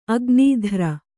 ♪ agnīdhara